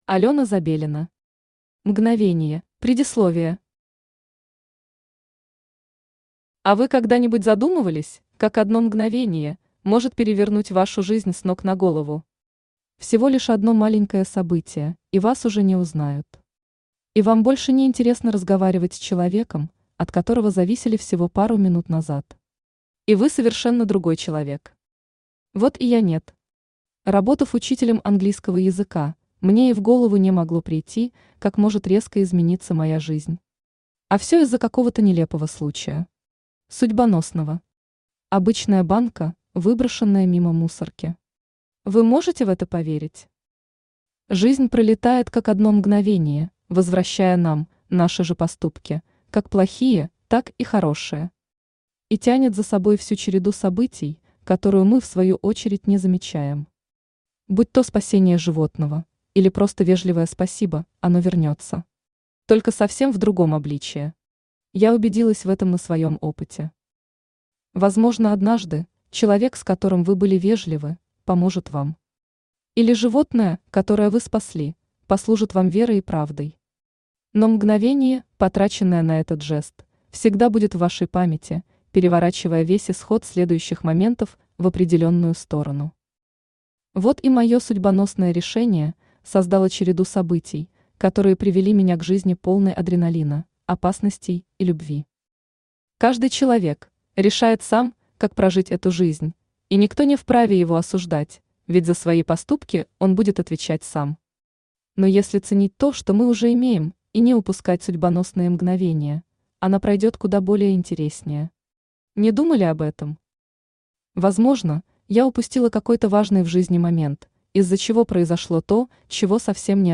Аудиокнига Мгновение | Библиотека аудиокниг
Aудиокнига Мгновение Автор Алена Забелина Читает аудиокнигу Авточтец ЛитРес.